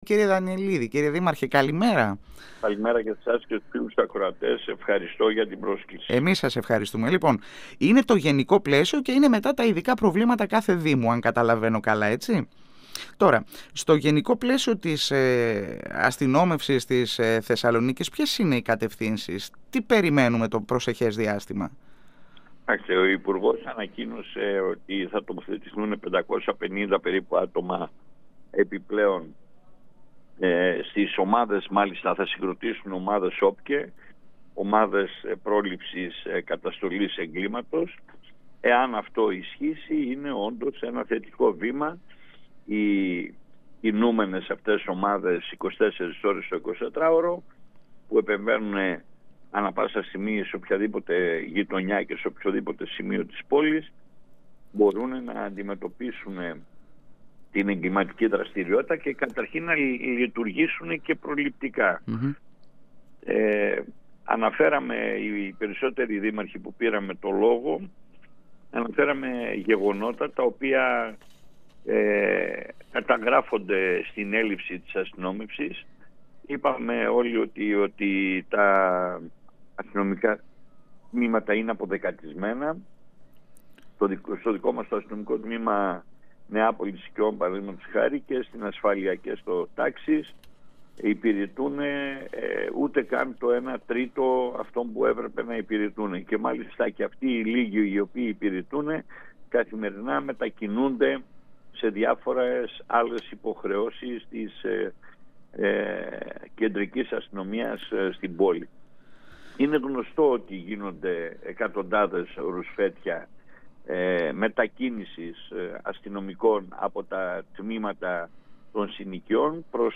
Στο νέο σχέδιο για την αστυνόμευση της Θεσσαλονίκης που παρουσίασε ο υπουργός Προστασίας του Πολίτη Μιχάλης Χρυσοχοΐδης στους 14 δημάρχους του νομού Θεσσαλονίκης αναφέρθηκε ο Δήμαρχος Νεαπόλεως–Συκεών Σίμος Δανιηλίδης μιλώντας στην εκπομπή «Εδώ και Τώρα» του 102FM της ΕΡΤ3.